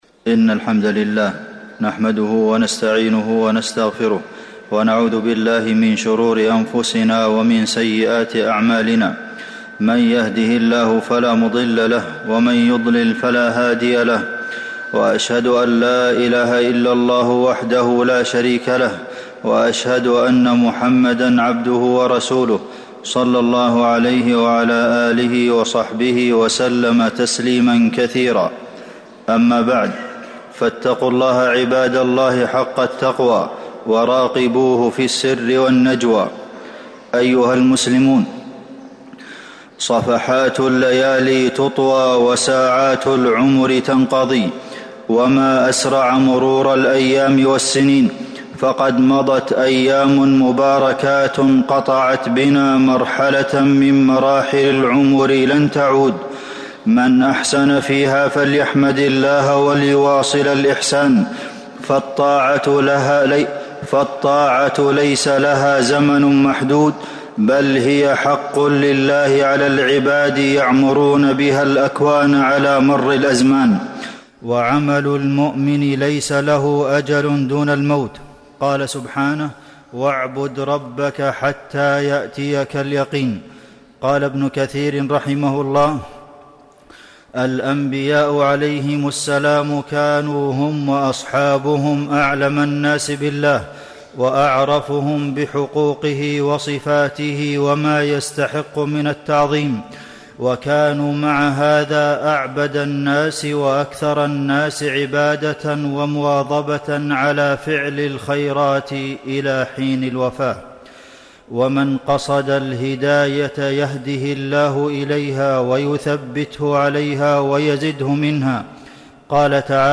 المدينة: حفظ الأعمال بعد رمضان - عبد المحسن بن محمد القاسم (صوت - جودة عالية